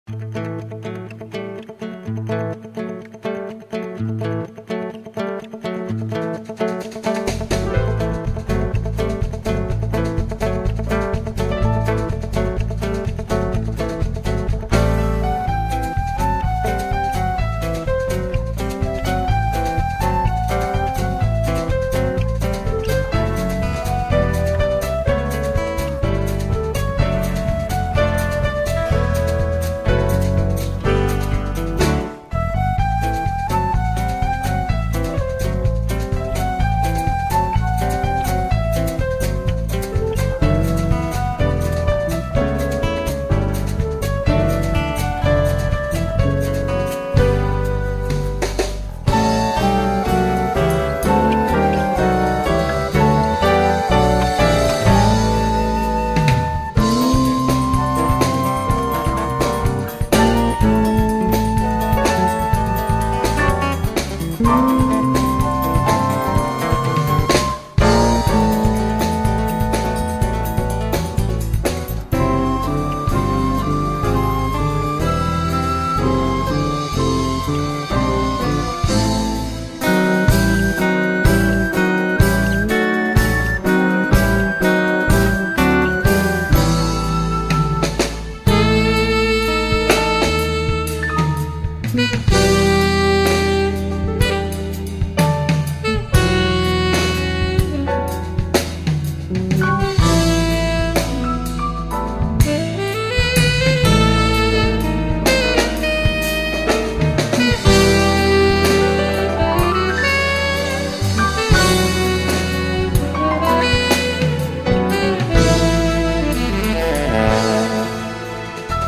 277   04:42:00   Faixa:     Jazz
Instrumental
Acoordeon
Guitarra
Flauta
Baixo Elétrico 6
Percussão